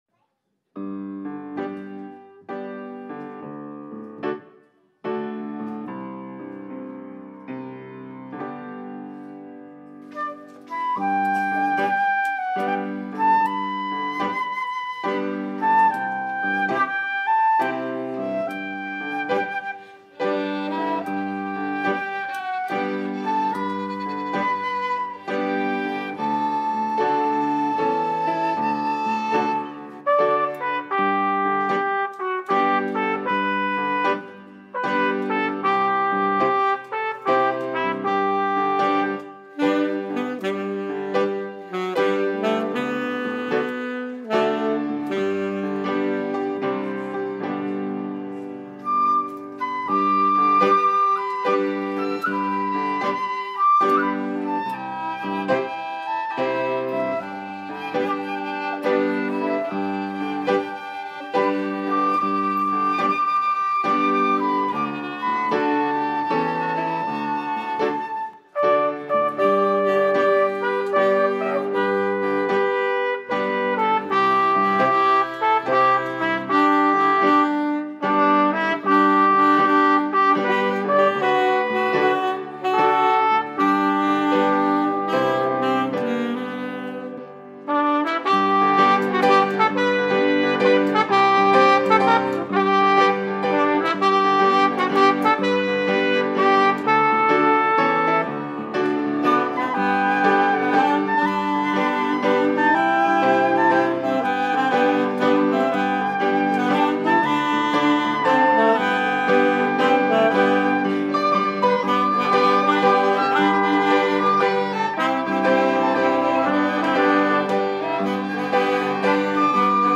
by VBC Teens Offertory | Verity Baptist Church